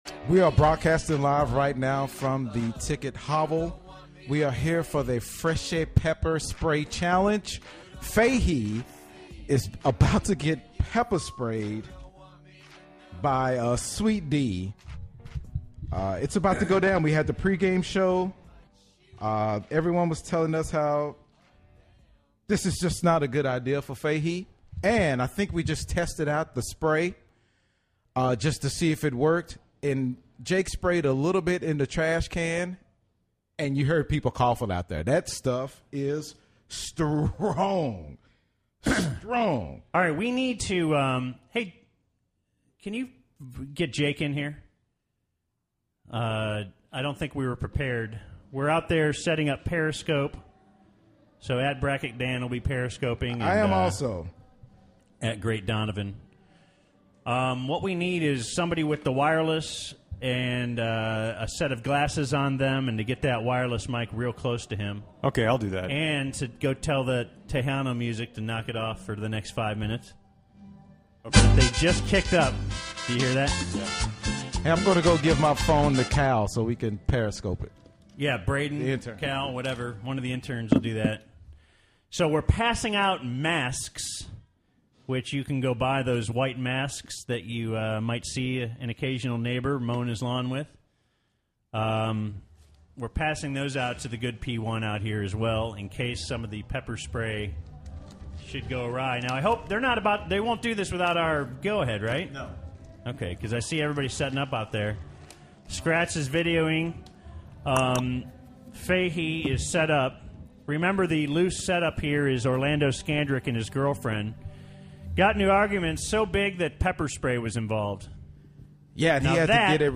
got pepper sprayed live on Bad Radio.